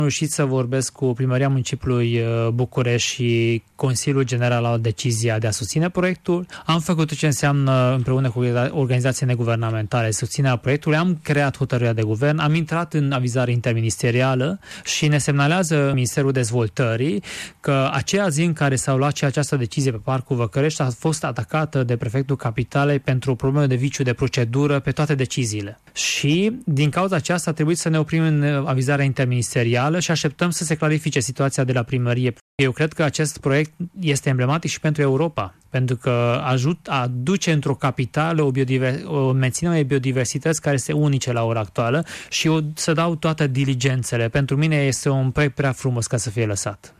Ministrul mediului, Attila Korodi.